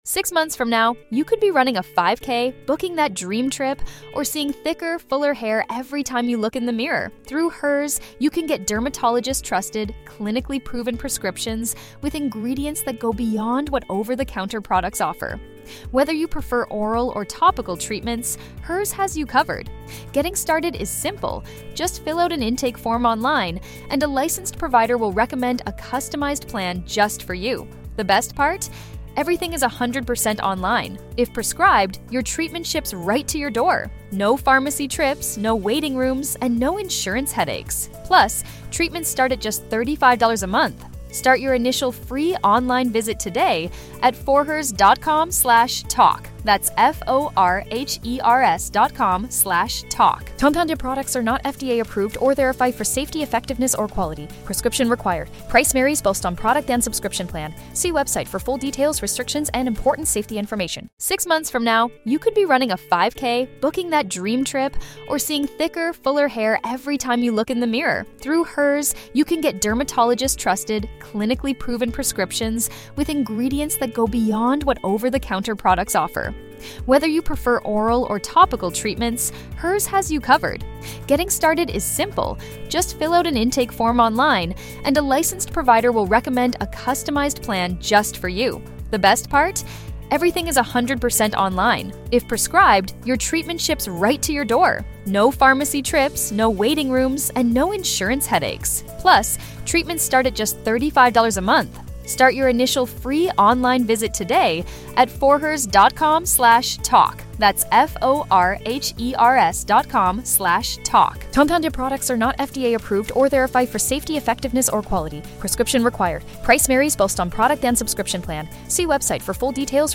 On this week's live show